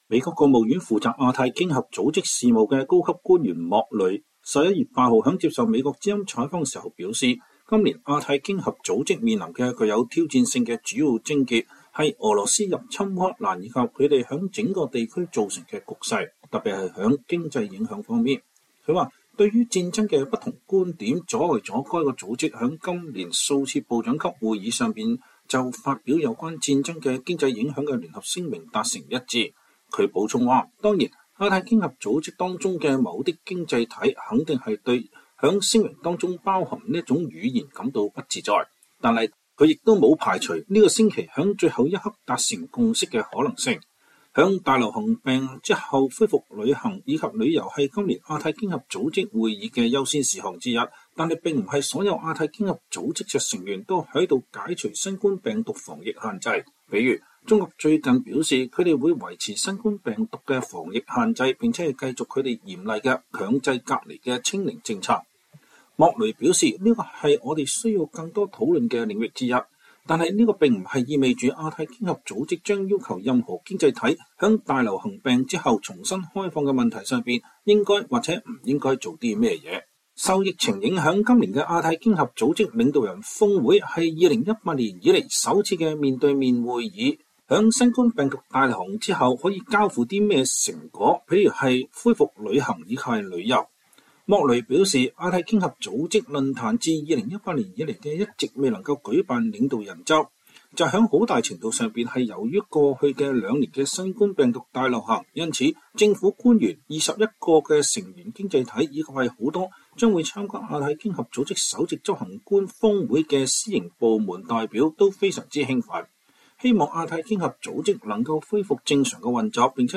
VOA專訪美國務院官員：在APEC框架內談俄烏戰爭、放鬆防疫旅行限制及華盛頓與北京和台灣的接觸